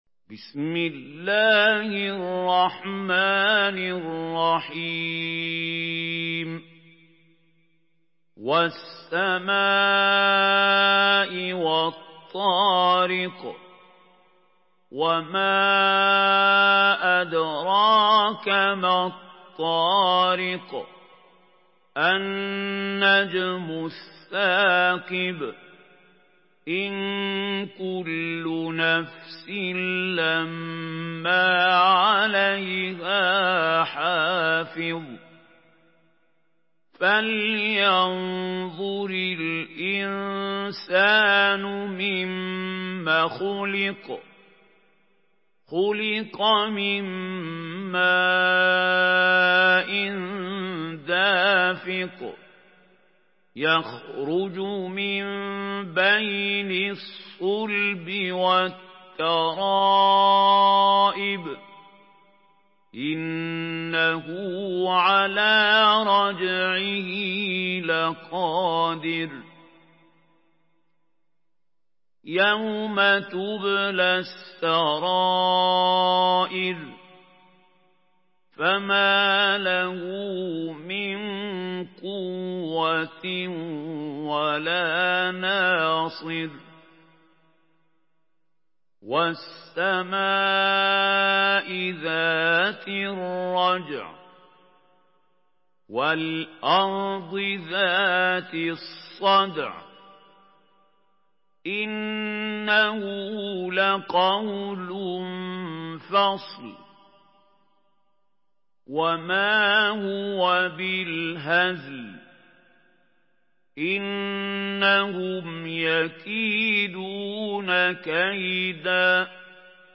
Surah الطارق MP3 by محمود خليل الحصري in حفص عن عاصم narration.
مرتل حفص عن عاصم